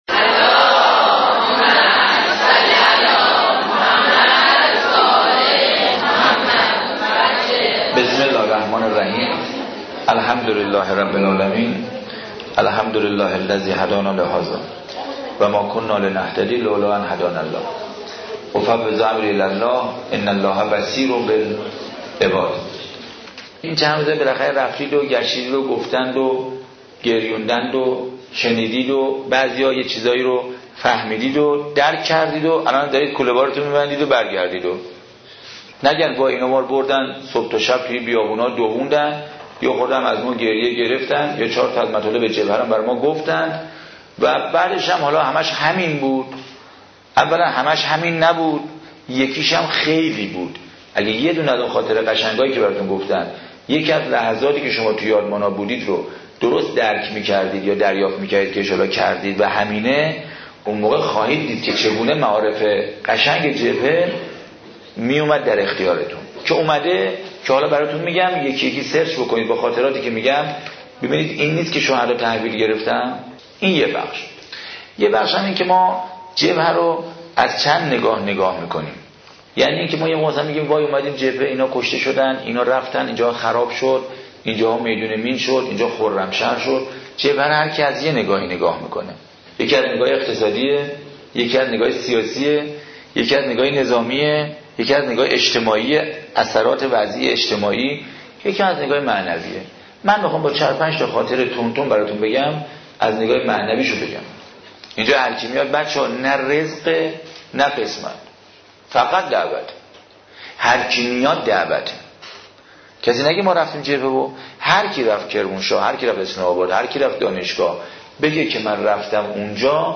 صوت روایتگری
ravayatgari86.mp3